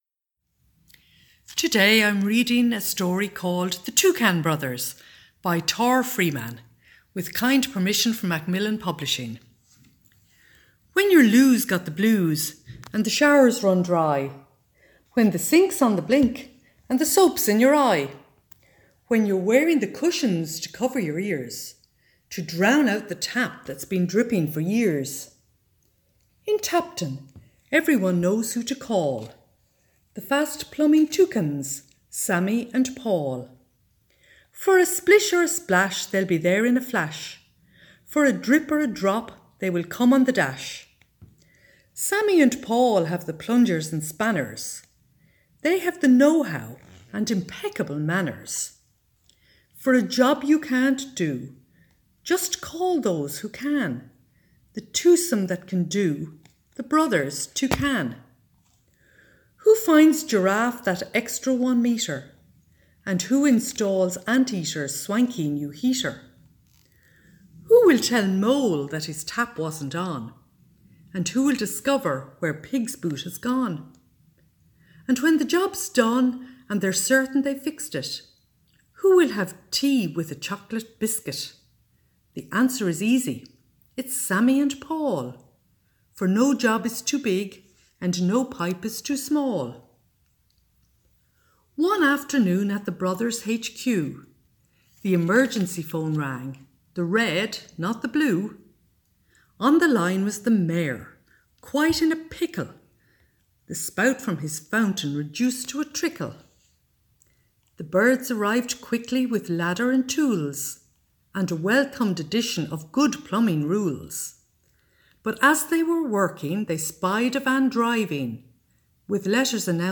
Storytime - The Toucan Brothers
Just click on the link below to listen: the-Toucan-Brothers.mp3 (size 4.5 MB) Join us every Wednesday and Saturday for storytime with library staff.